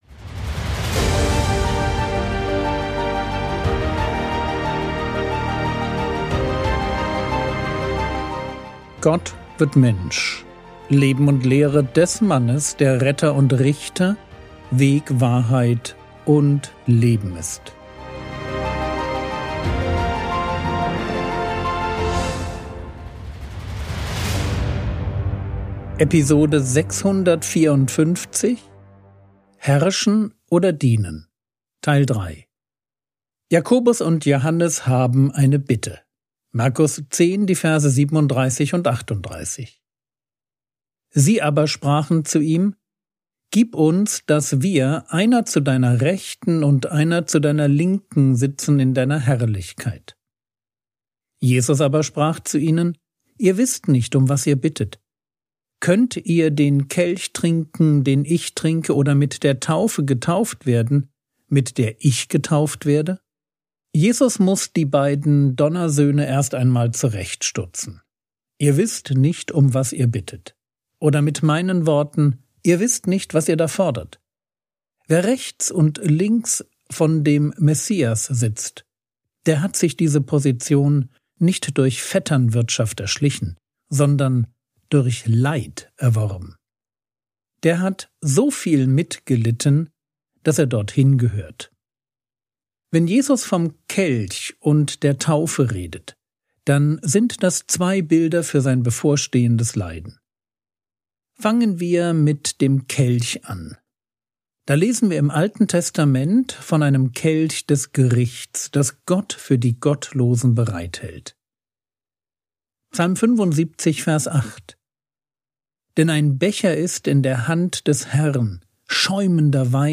Episode 654 | Jesu Leben und Lehre ~ Frogwords Mini-Predigt Podcast